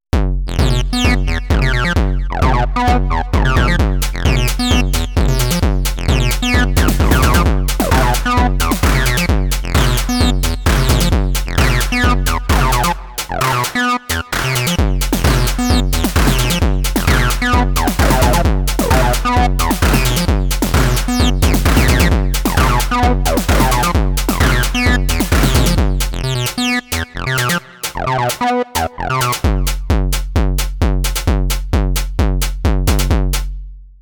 Filter -> Overdrive = Equals putting an Overdrive Pedal after a Synth
A bit acid :wink: